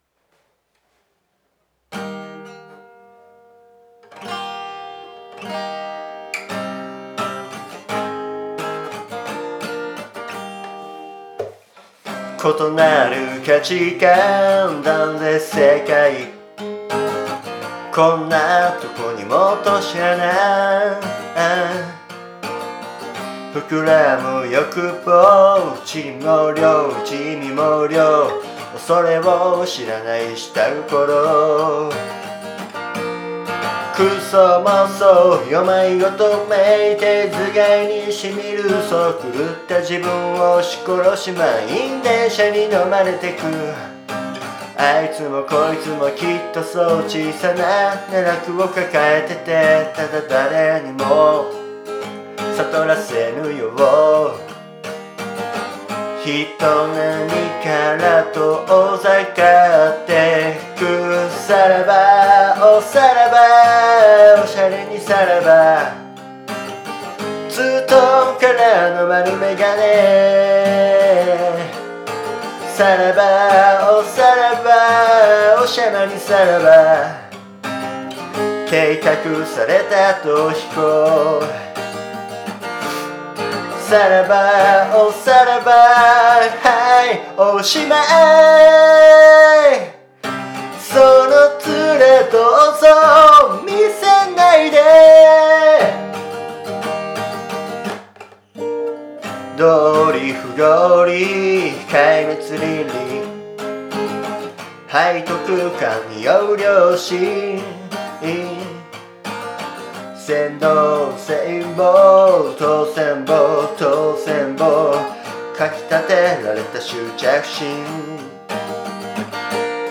家でボリュームを気にしつつ歌ってみたよ。
くしゃみと鼻水が止まんなくて苦しそうでごめんね。